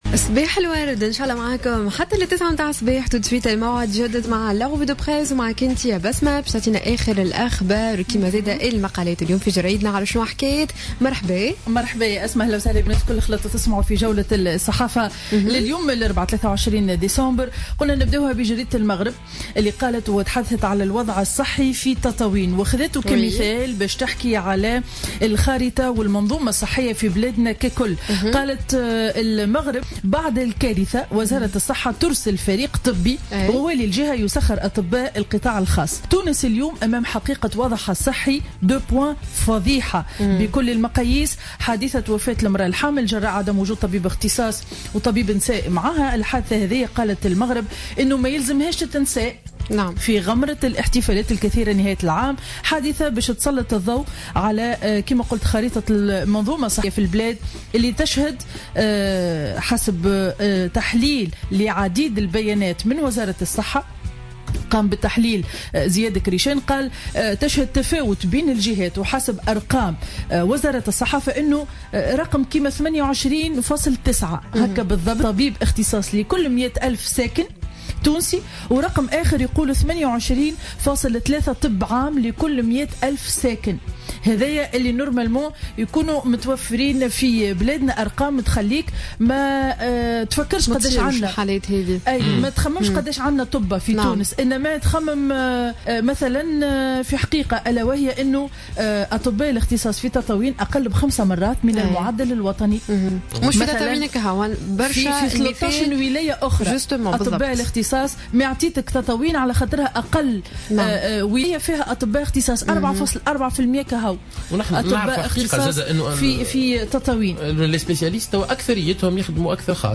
Revue de presse du 23 décembre 2015